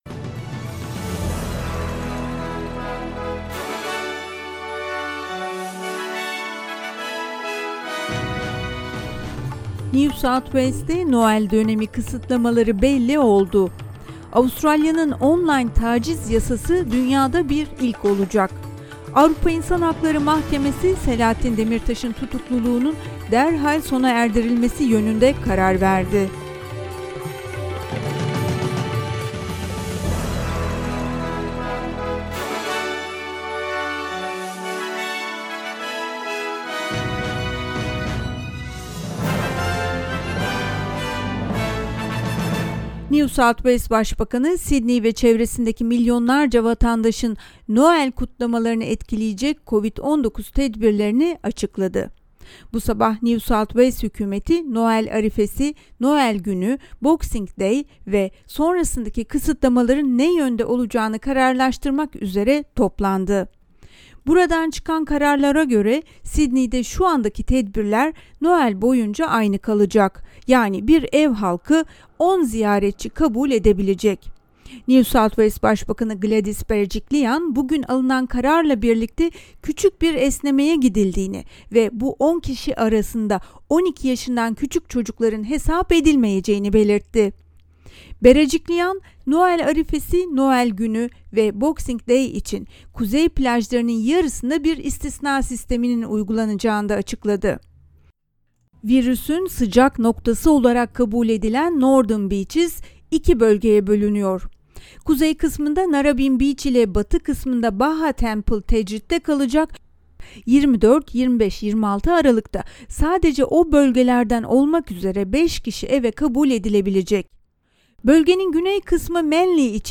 SBS Türkçe Haberler 23 Aralık
turkish_23122_news.mp3